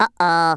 Worms speechbanks
uhoh.wav